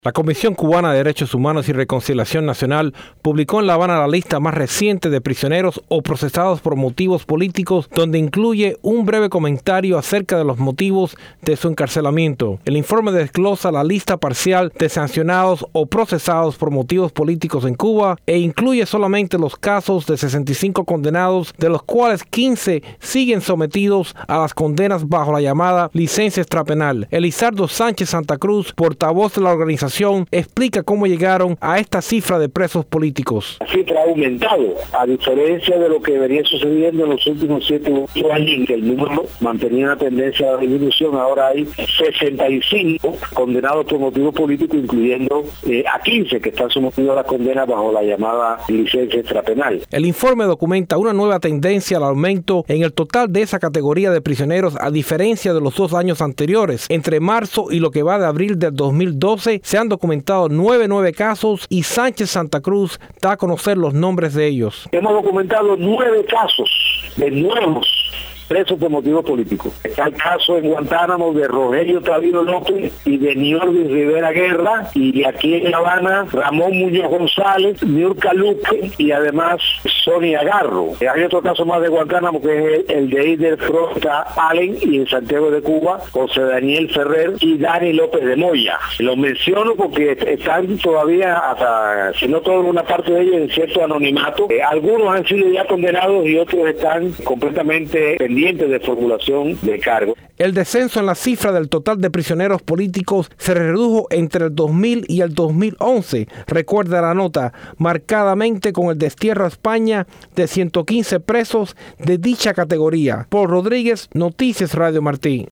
Reporte de Radio Martí